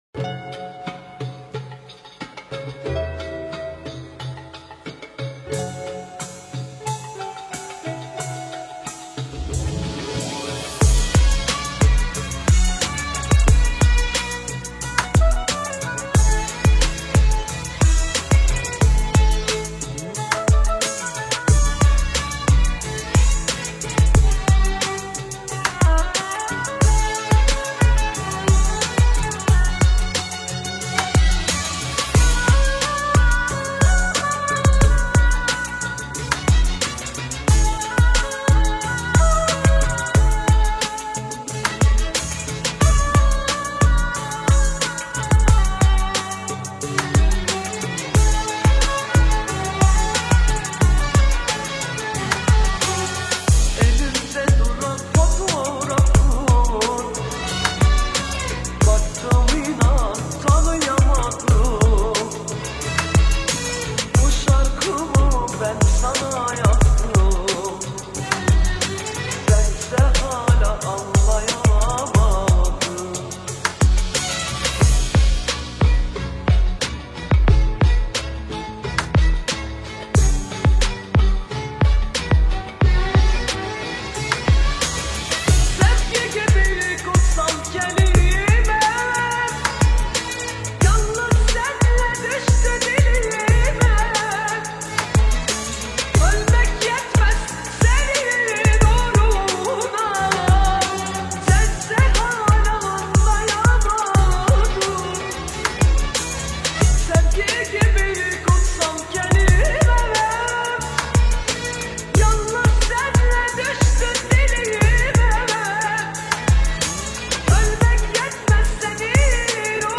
آهنگ ترکیه ای آهنگ غمگین ترکیه ای آهنگ نوستالژی ترکیه ای